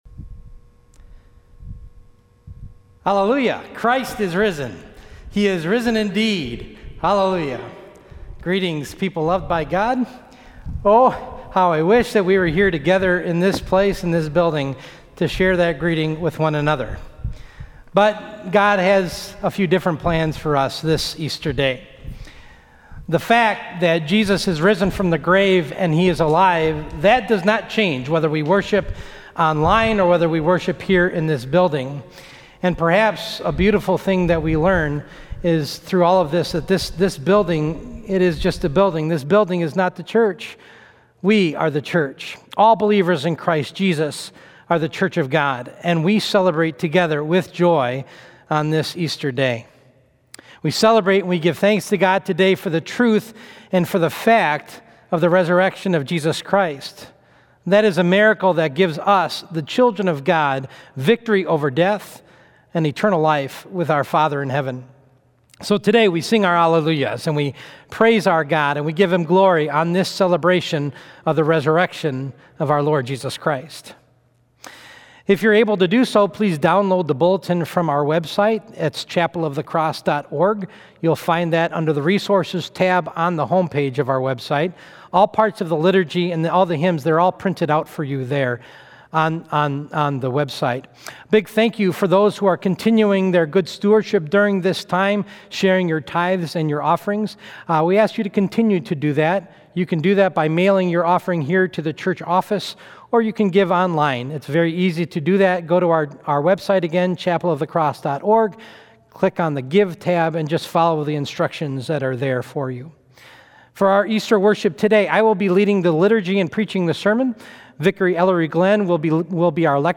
Easter Sunday Worship 4-12-20
Easter Sunday Worship Online at Chapel of the Cross - Lutheran in St. Louis, MO.